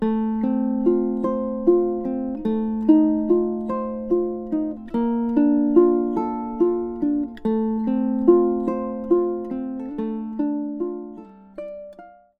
arpeggio icon P-i-m-a-m-i Arpeggio
Here's what P-i-m-a-m-i looks and sounds like when applied to the chords in the first line of Amazing Grace: